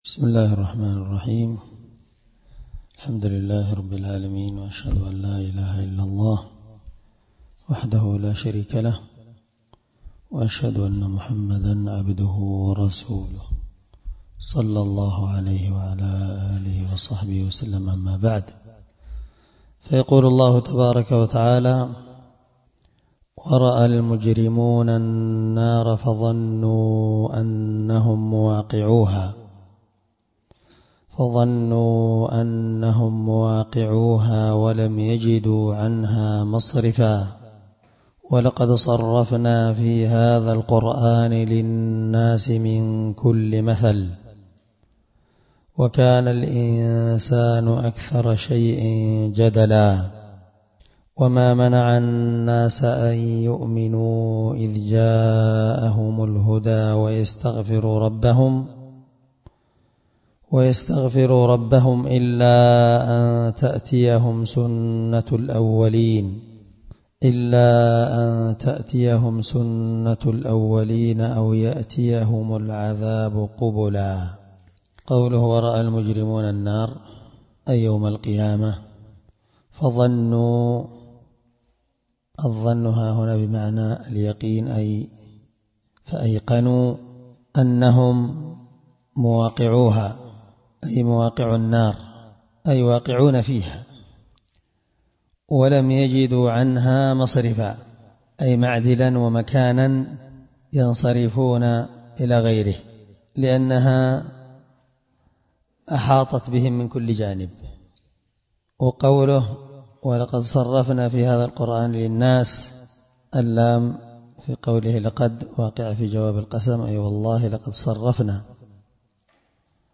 18سورة الكهف مع قراءة لتفسير السعدي